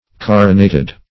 Carinate \Car"i*nate\, Carinated \Car"i*na`ted\a. [L. carinatus,